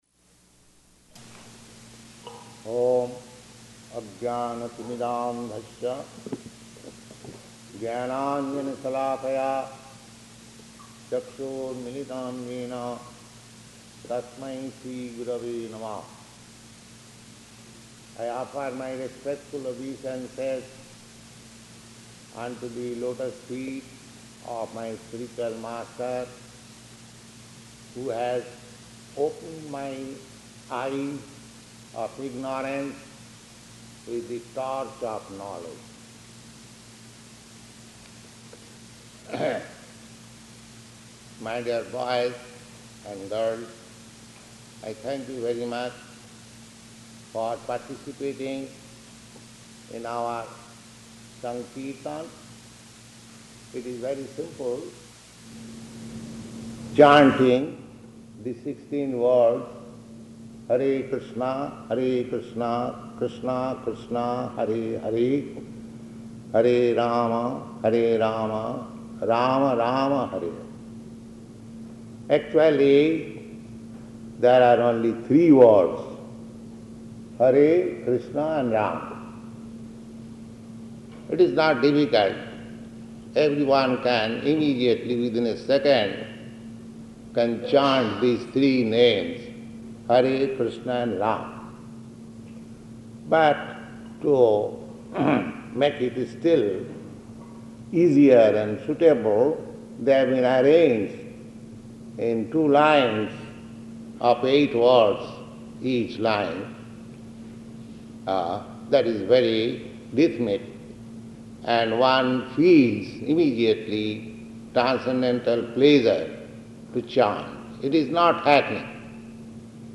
Brandeis University Lecture